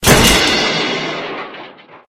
metal_joint_break_03.ogg